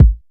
Kick (2).wav